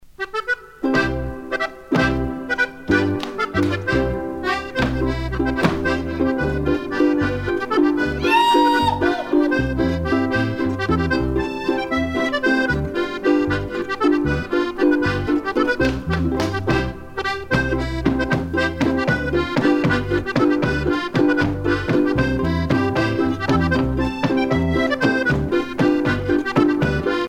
danse : schuhplattler (Bavière)
Pièce musicale éditée